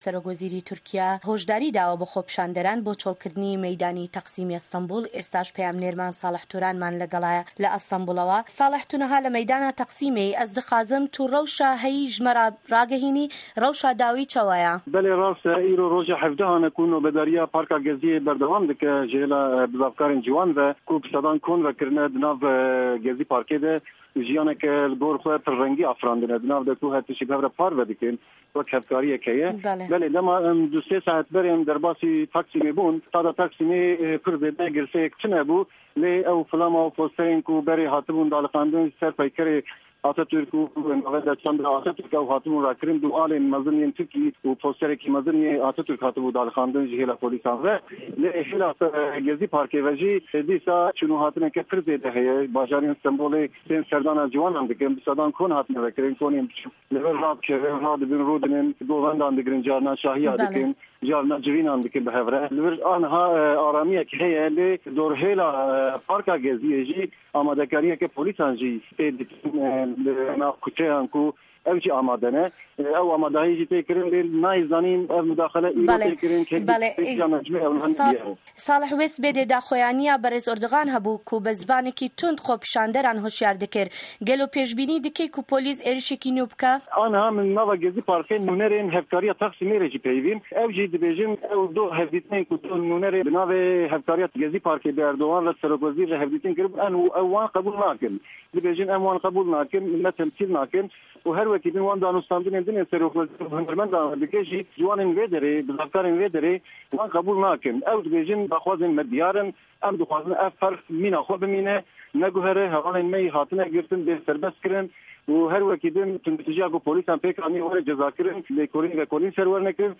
Raporta Zindî ya Ser Rewşa Gezî Parki